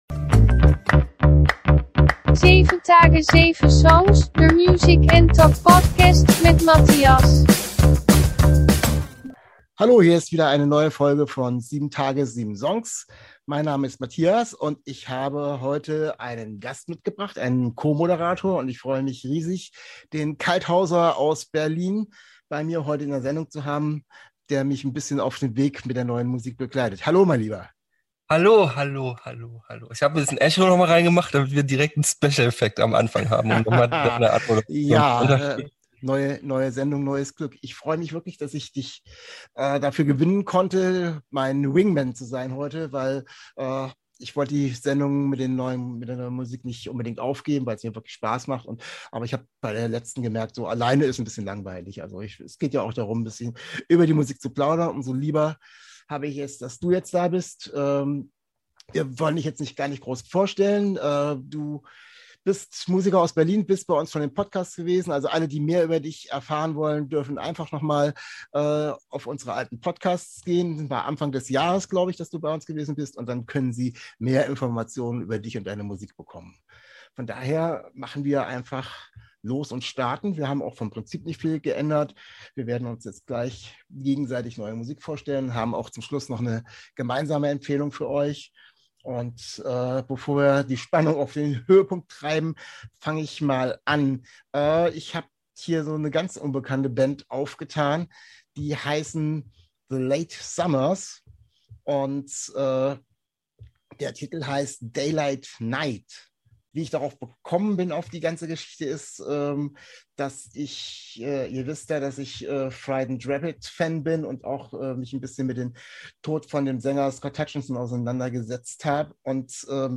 Wir stellen uns gegenseitig neu Musik vor und plaudern rund um das Thema Musik.